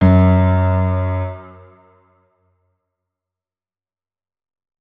main Divergent / mods / Hideout Furniture / gamedata / sounds / interface / keyboard / piano / notes-18.ogg 54 KiB (Stored with Git LFS) Raw Permalink History